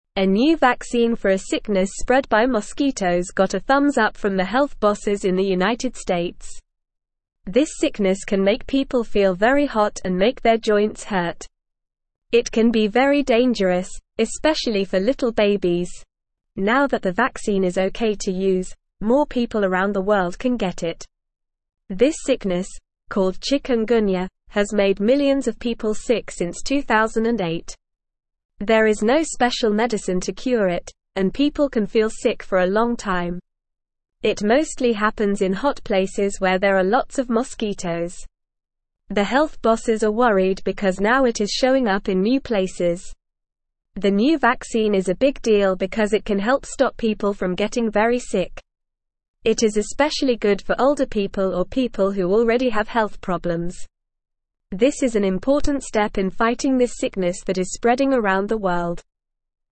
Normal
English-Newsroom-Lower-Intermediate-NORMAL-Reading-New-shot-to-stop-bad-bug-bite-sickness.mp3